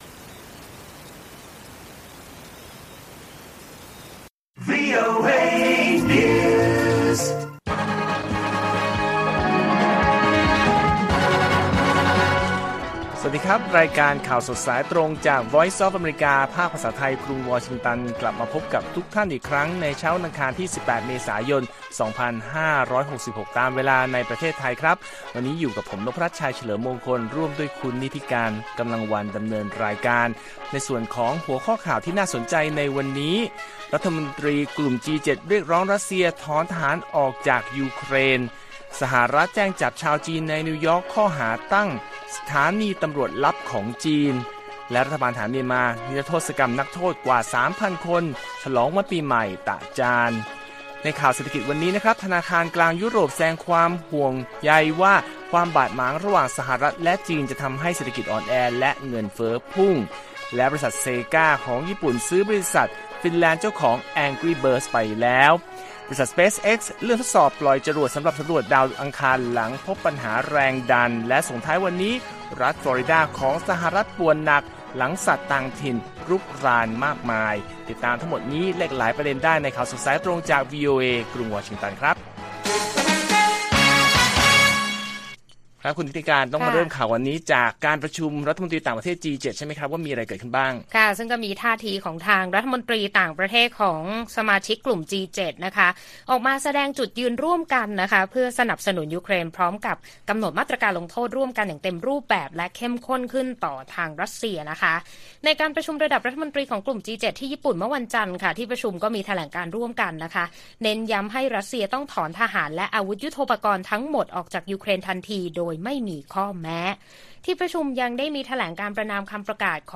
ข่าวสดสายตรงจากวีโอเอไทย 6:30 – 7:00 น. วันที่ 18 เม.ย. 2566